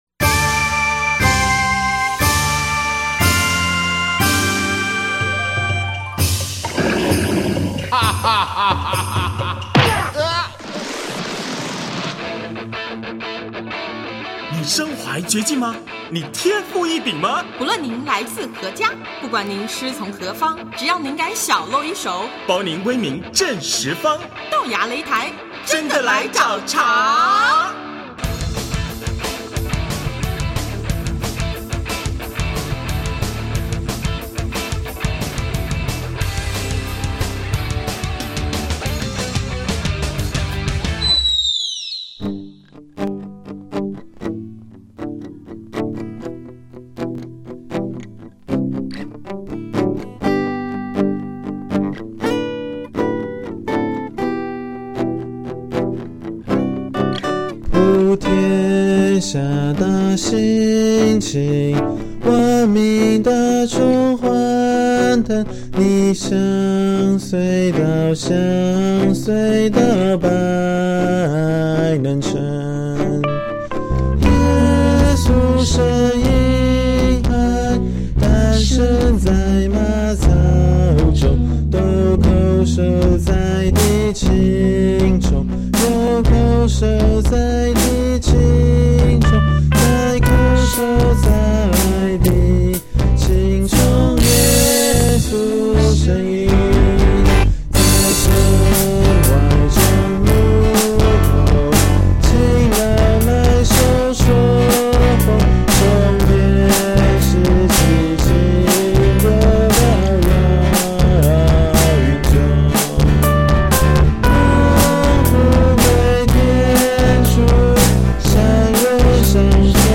圣诞佳节，怀仁乐团用变奏的圣诞歌曲，为朋友传报佳音。